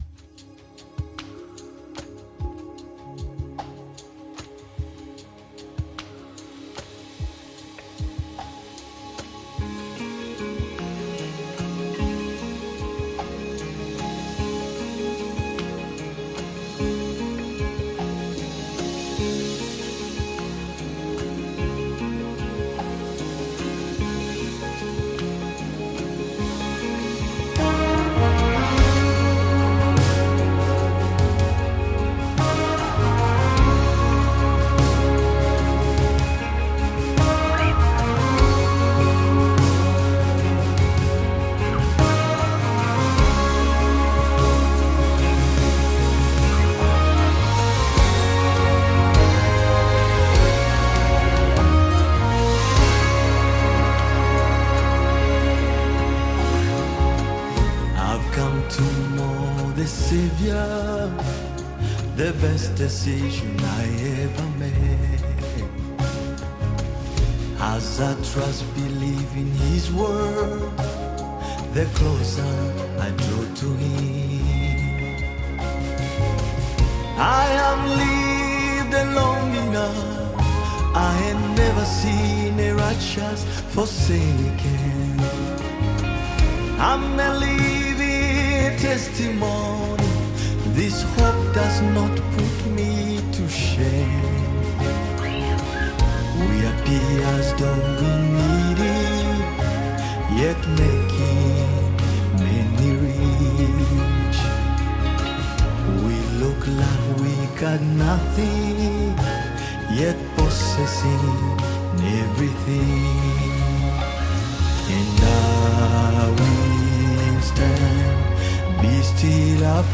Nyimbo za Dini Praise music
Praise Gospel music track